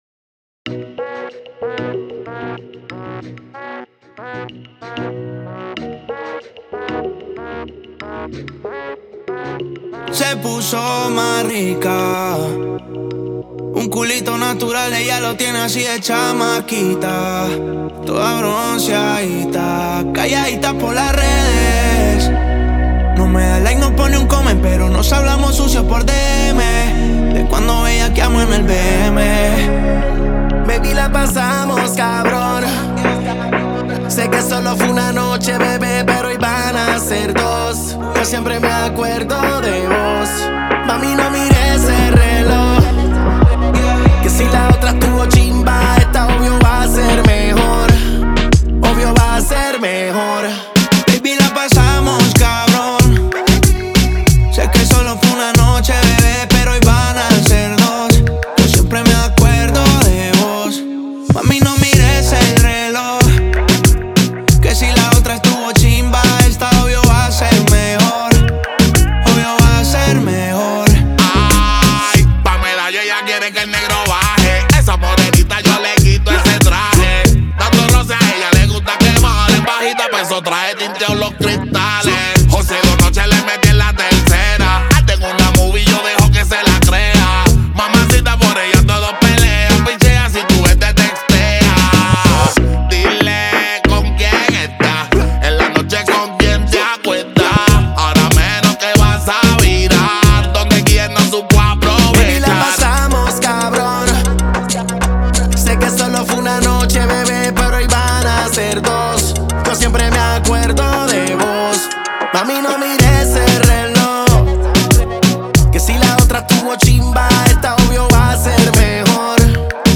Genre: Ton Ton.